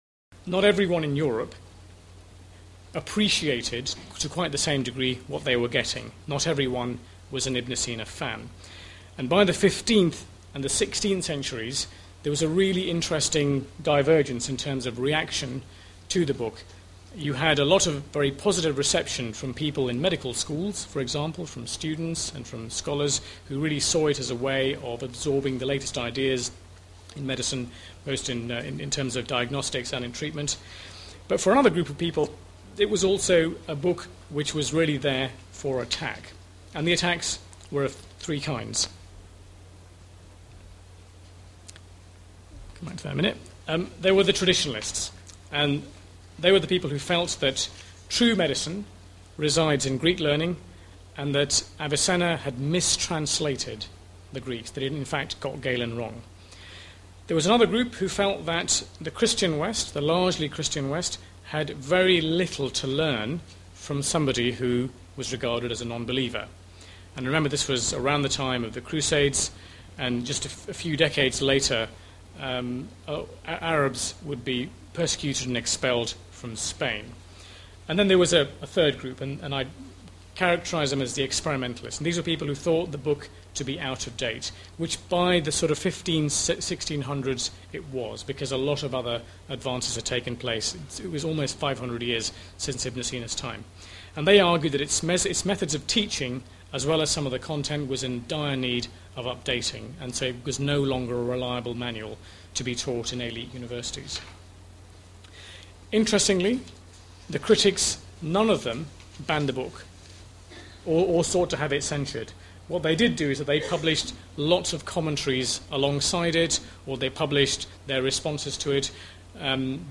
Description: Clip 3 from the Science Festival Spotlight on Science lecture, Science and Islam